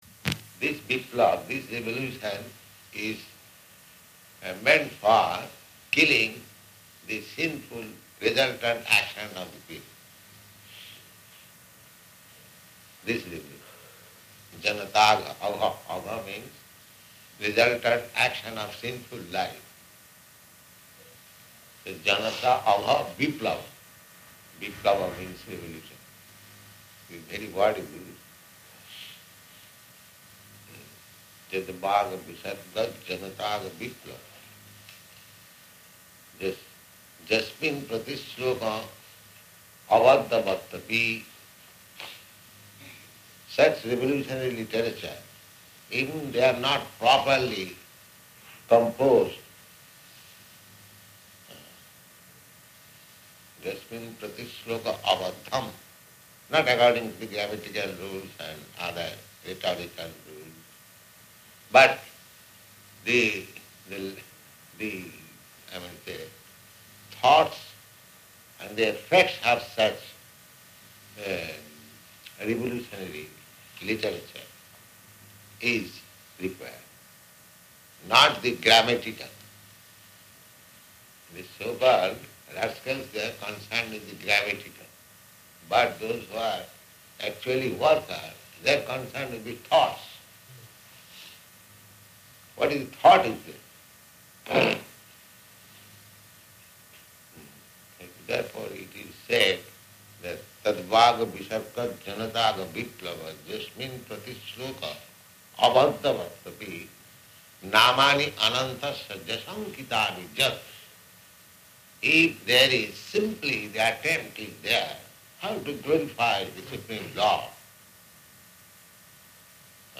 Room Conversation on SB 1.5.11
Type: Conversation
Location: Jaipur